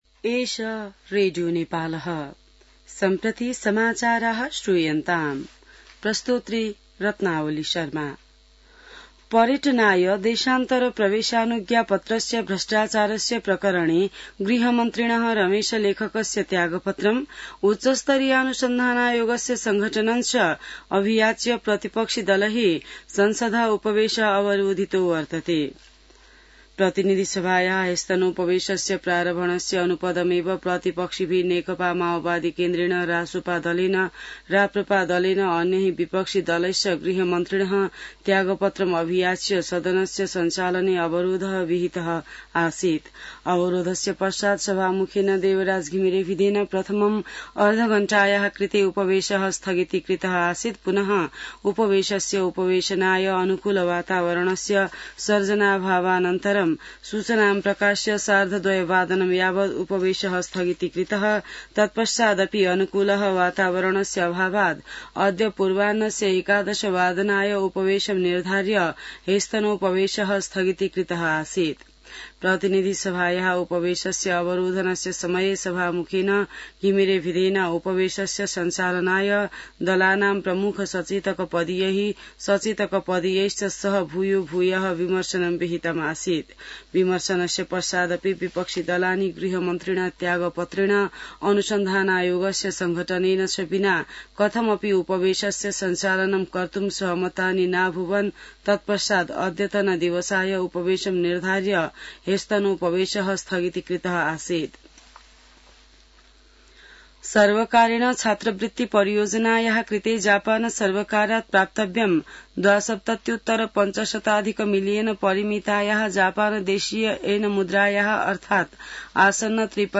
संस्कृत समाचार : १४ जेठ , २०८२